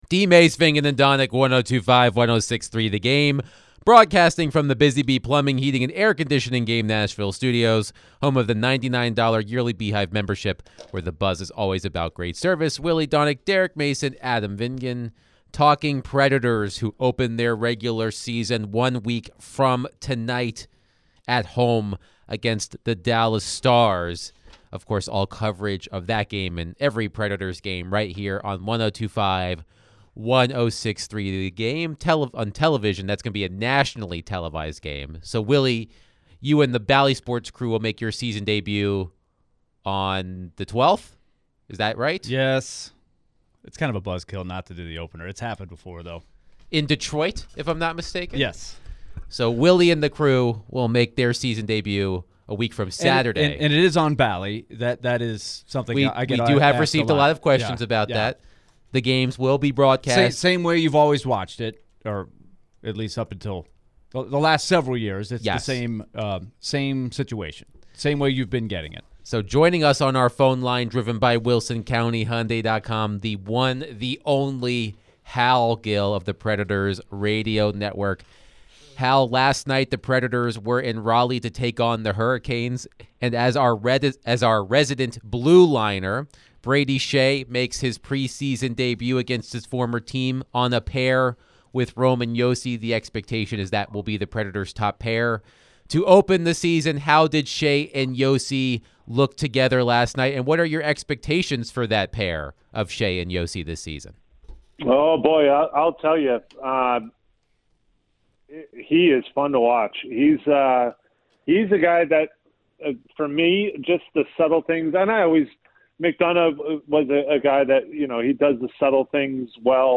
Predators Radio Analyst Hal Gill joined the show at the top of the hour to discuss how the Predators look right now in training camp.